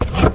High Quality Footsteps / Wood / Wood, Dense
MISC Wood, Foot Scrape 03.wav